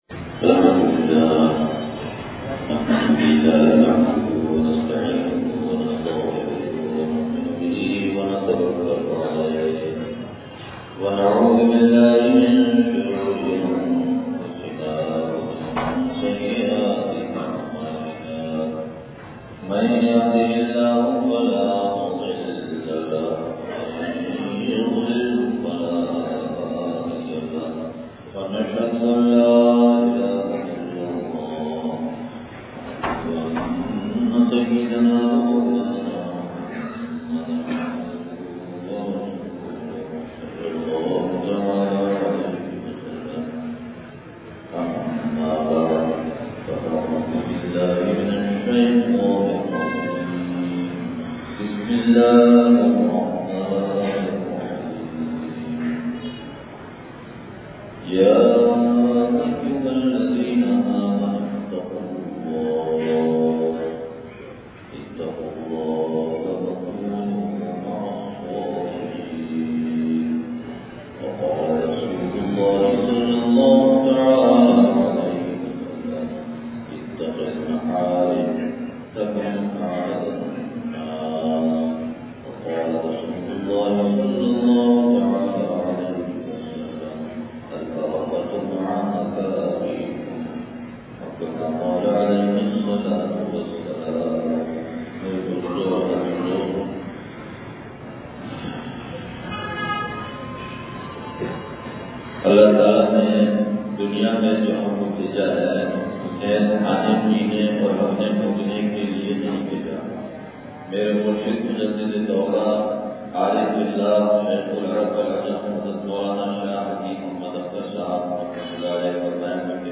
مونی مسجد کپڑا مارکیٹ نواب شاہ سندھ (بعد عصر بیان)